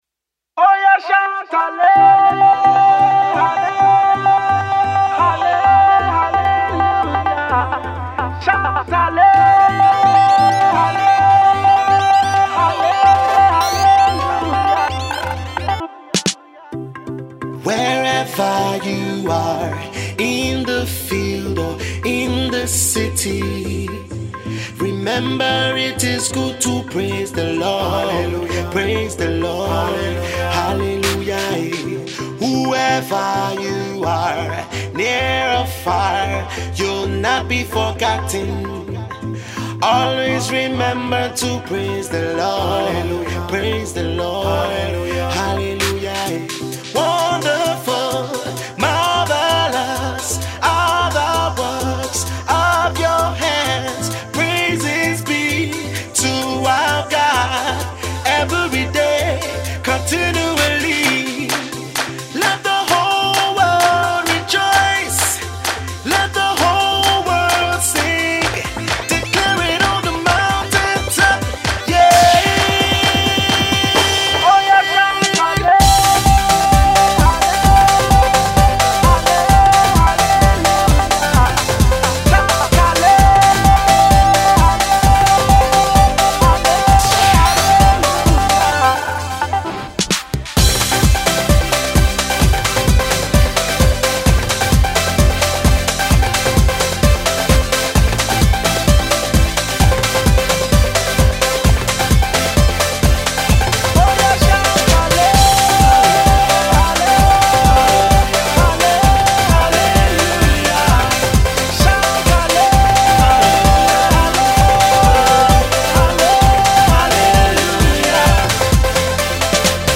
gospel duo
sound you cannot but move to